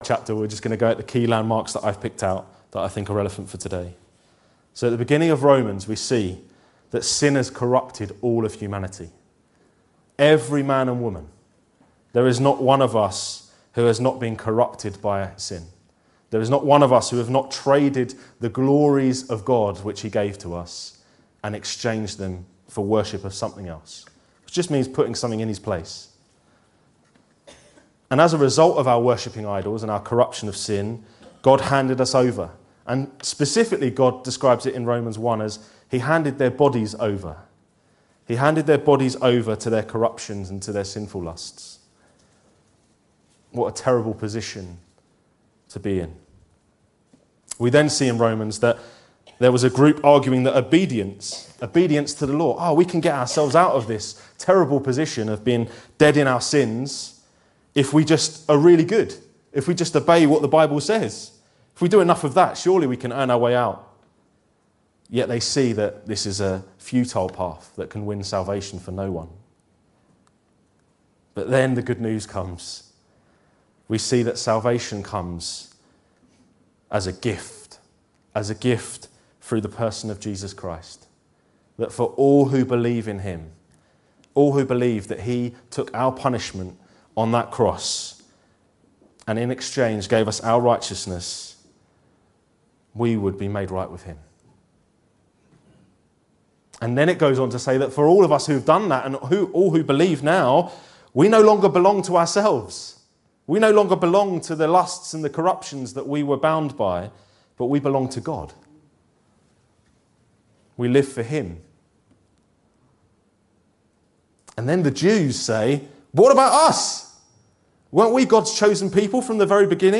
This sermon is from our 2025 International Sunday. It is a corporate call to present our bodies as a holy sacrifice to God in worship.